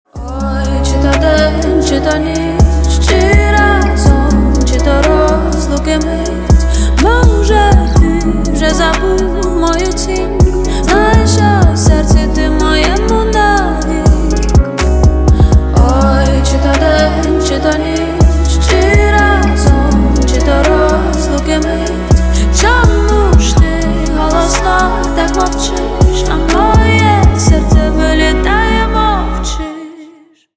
Поп Музыка
грустные # спокойные